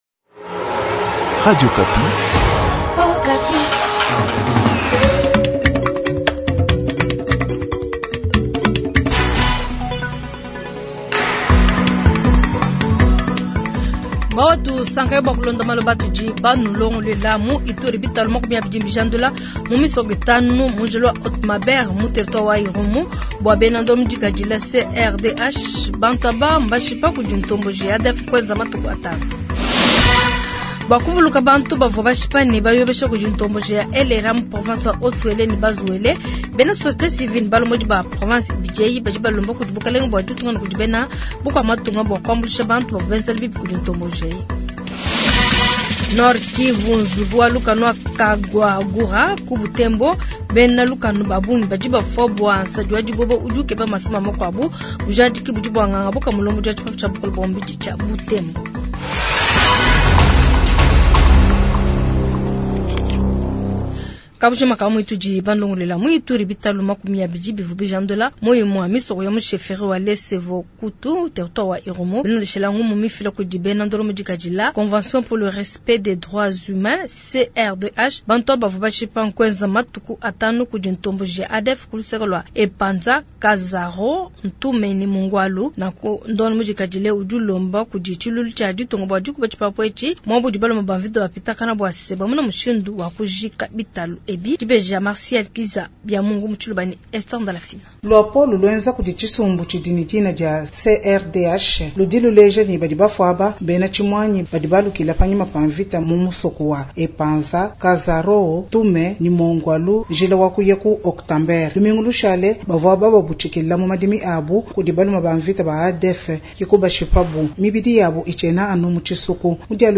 Jounal soir